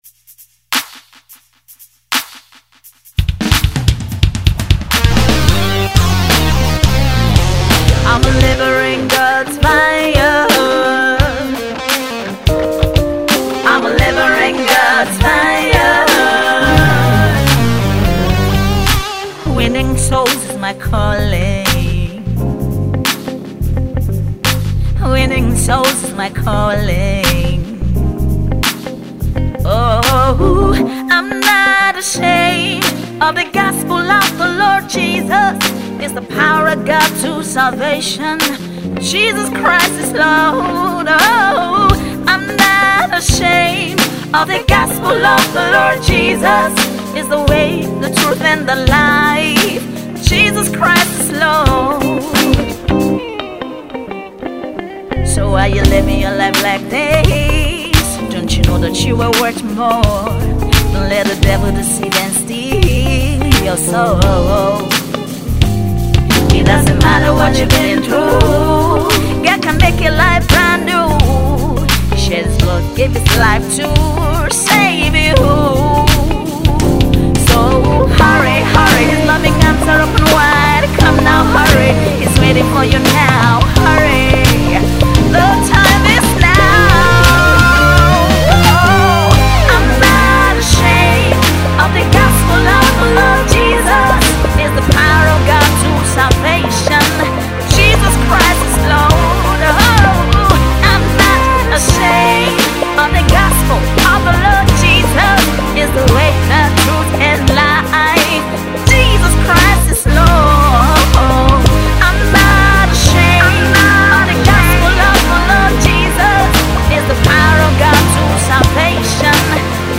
a good blend of Funk, Soul and Rnb